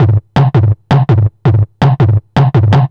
Index of /90_sSampleCDs/Zero-G - Total Drum Bass/Drumloops - 2/track 32 (165bpm)